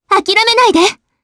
Frey-Vox_Skill1_jp.wav